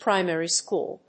アクセントprímary schòol